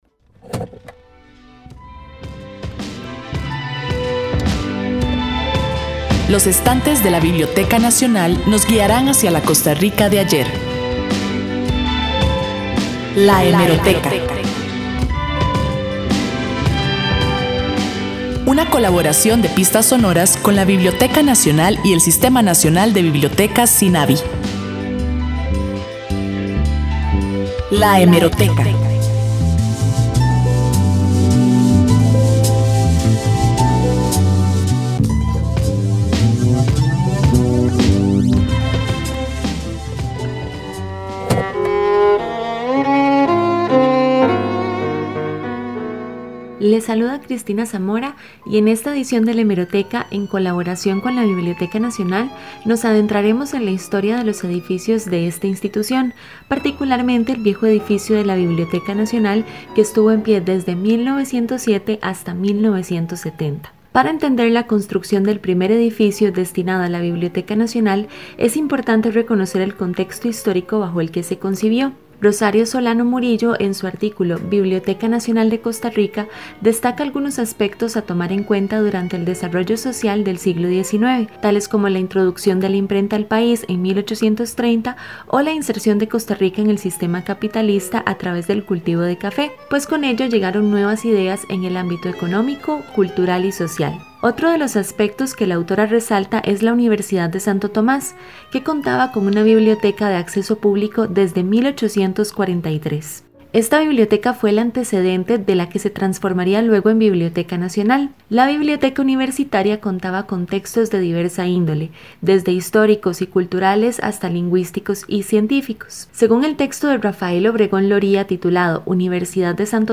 Sección de la Biblioteca Nacional en el Programa Pistas Sonoras de Radio Universidad, transmitido el 7 de agosto del 2021.